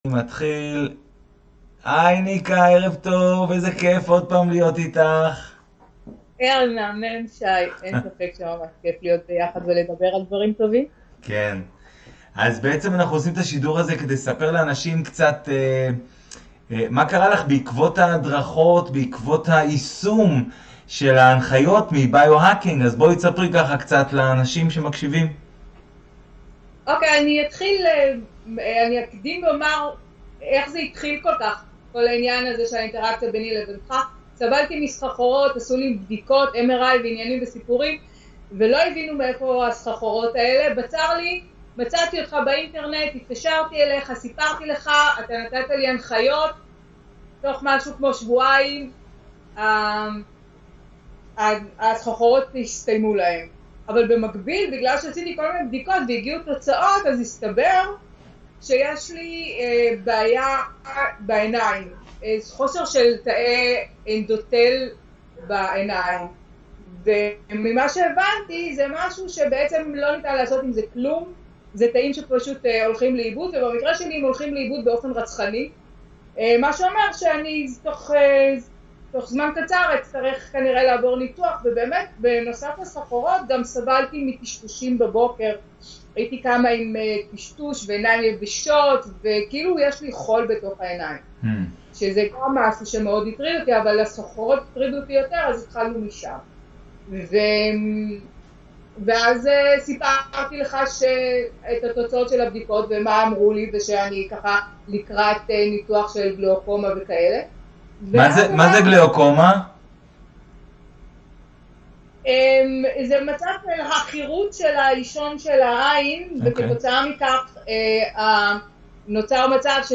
ראיון קצר